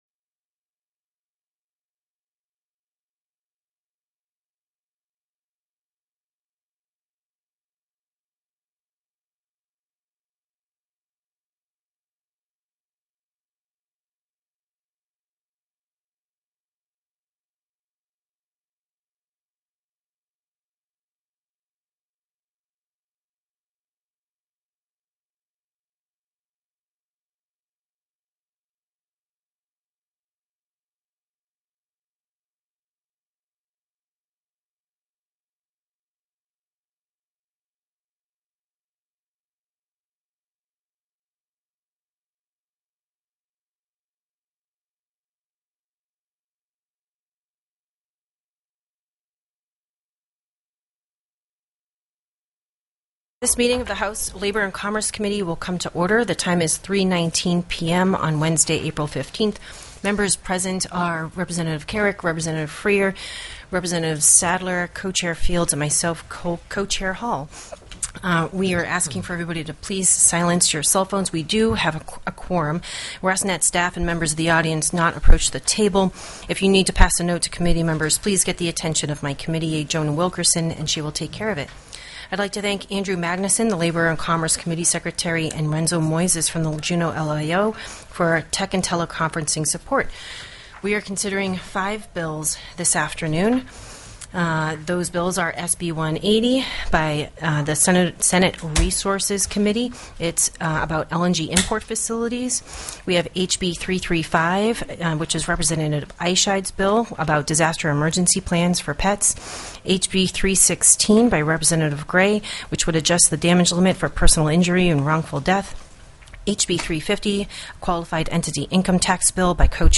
The audio recordings are captured by our records offices as the official record of the meeting and will have more accurate timestamps.
TELECONFERENCED
Invited & Public Testimony